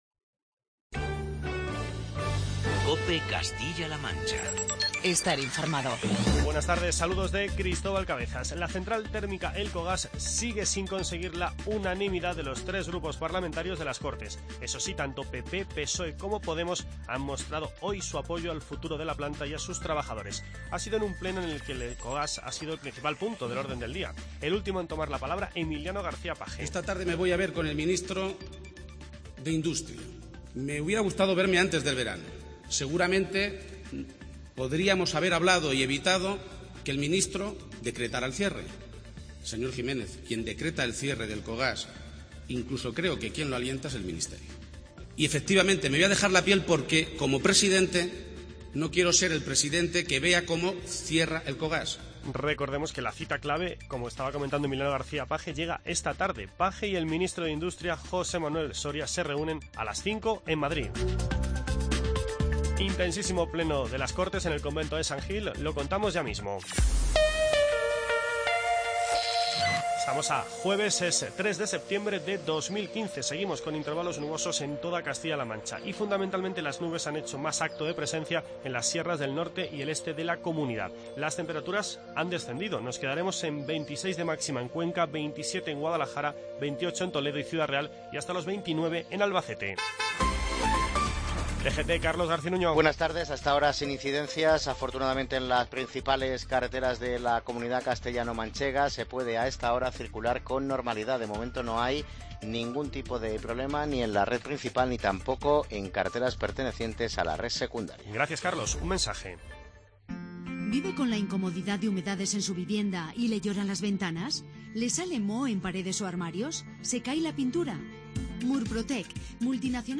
Elcogas o las declaraciones de Rajoy en COPE sobre el trasvase Tajo-Segura son algunos de los principales asuntos de este informativo